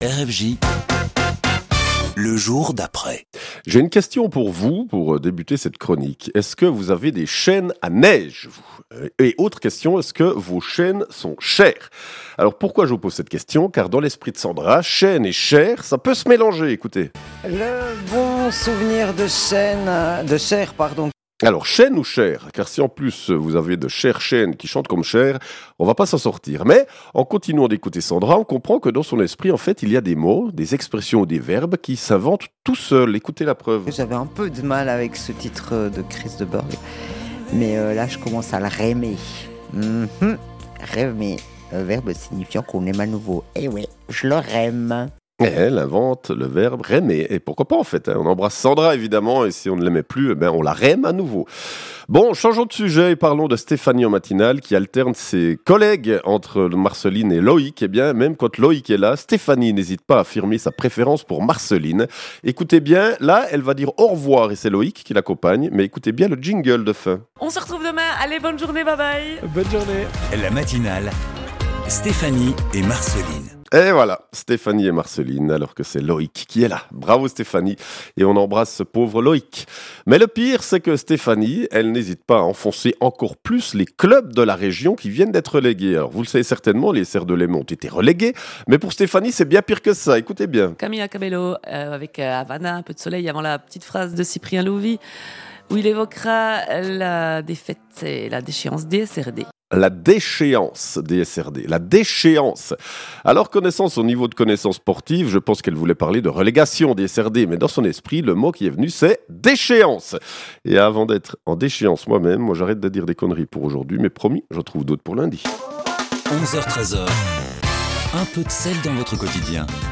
L'actu de la veille, traitée de manière un peu décalée, sons à l'appui, c'est ça